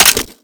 weap_sml_gndrop_2.wav